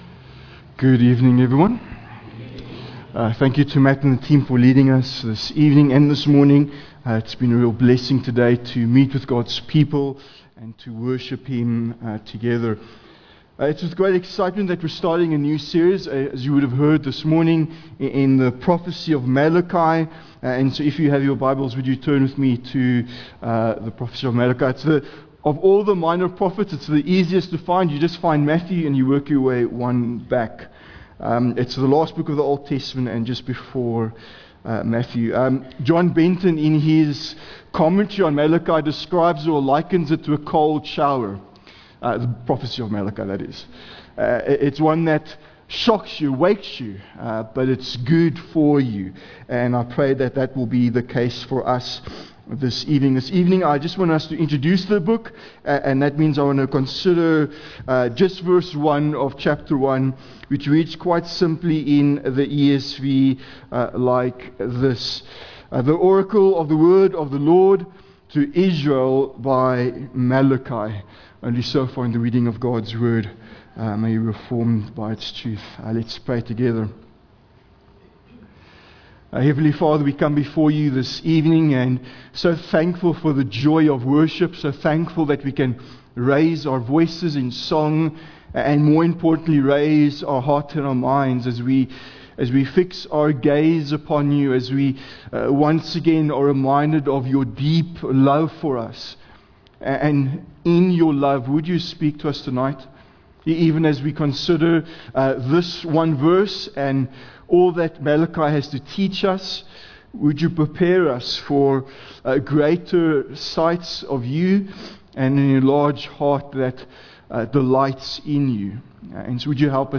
The book of Malachi exist for people who need spiritual renewal. Renewal requires, renouncing oneself, a relationship with God, realignment to God's word and refocusing on the gospel. This sermon is an introduction to the book of Malachi.